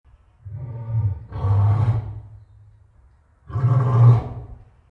Lion Growls Wav Bouton sonore